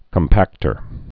(kəm-păktər, kŏmpăk-)